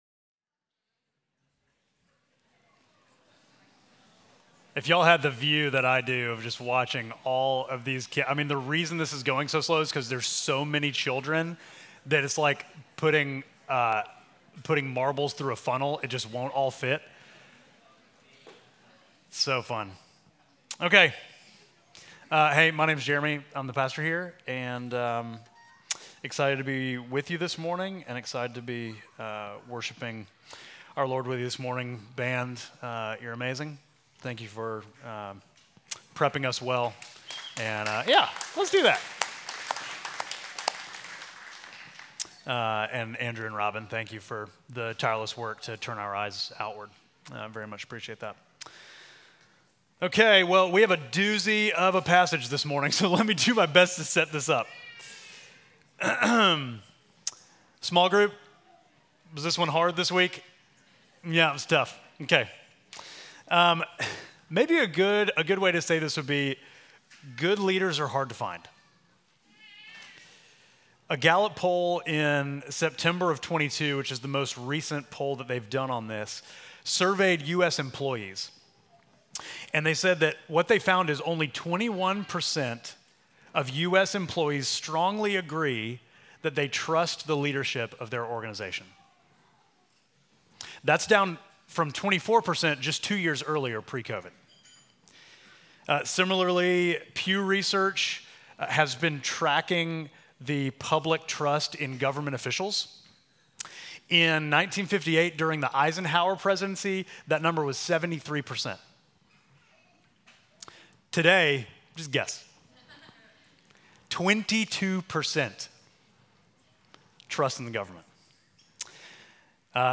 Midtown Fellowship Crieve Hall Sermons Abimelech: The Anti-Savior Oct 27 2024 | 00:33:45 1x 00:00 / 00:33:45 Subscribe Share Apple Podcasts Spotify Overcast RSS Feed Share Link Embed